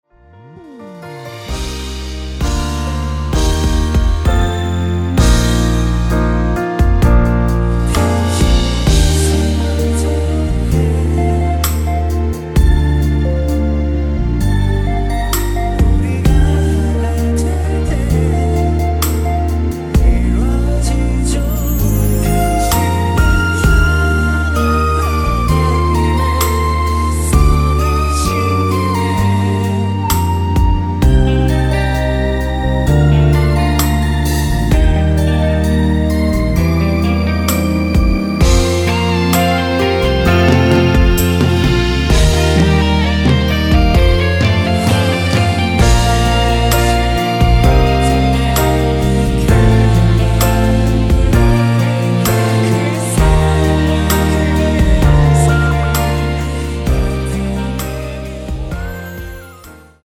원키 코러스 포함된 MR 입니다.
Bb
앞부분30초, 뒷부분30초씩 편집해서 올려 드리고 있습니다.
중간에 음이 끈어지고 다시 나오는 이유는